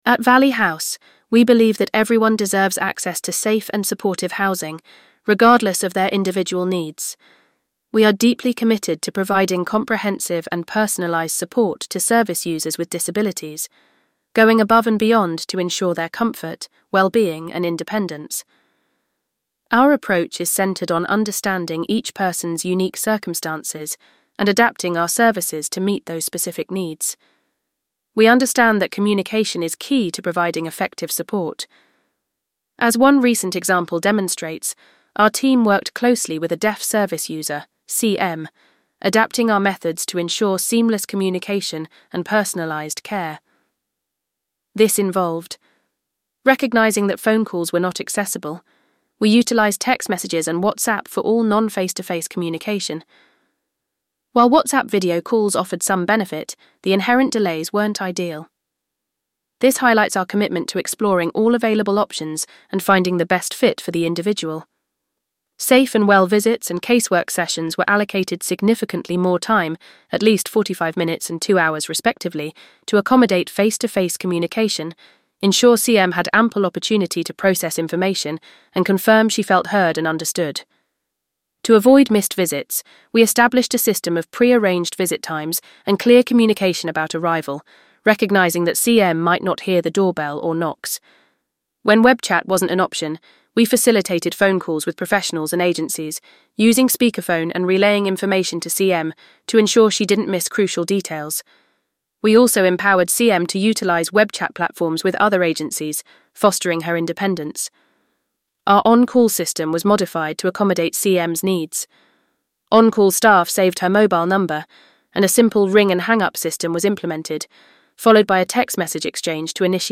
VOICEOVER-Disability-Care.mp3